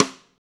gretsch rimshot ff.wav